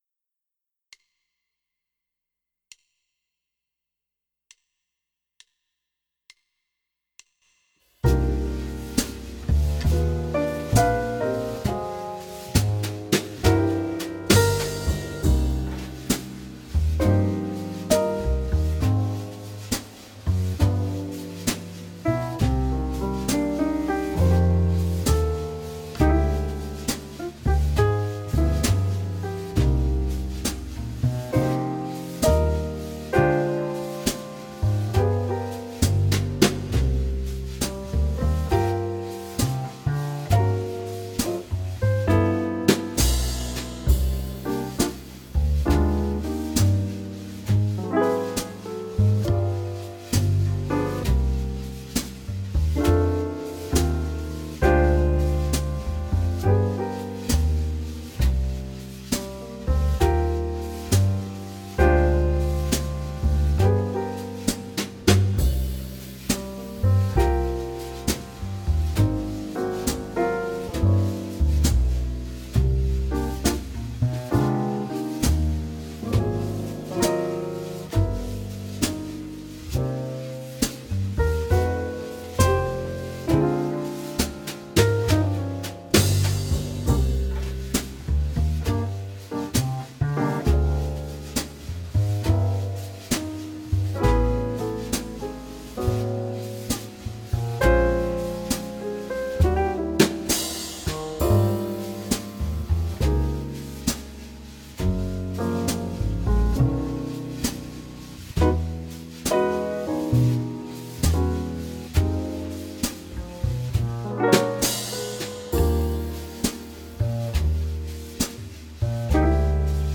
a nice down tempo tune